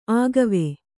♪ āgave